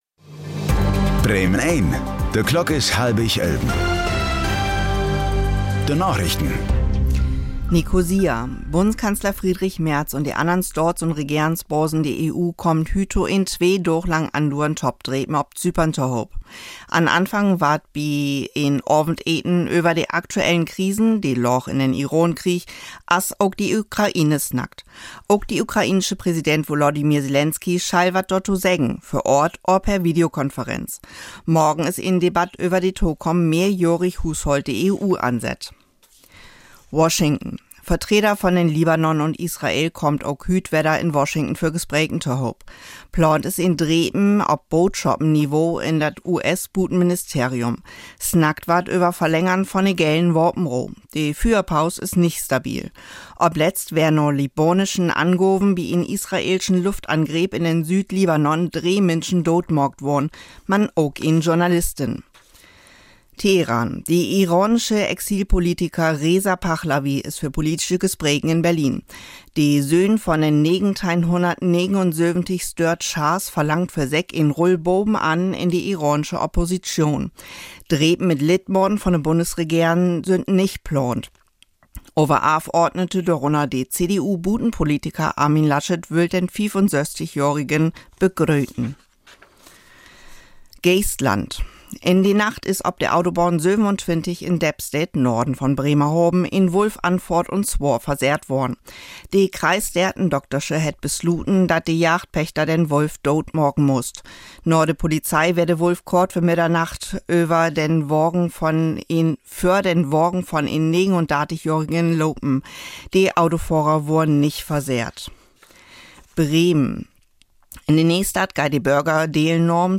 Plattdüütsche Narichten vun'n 23. April 2026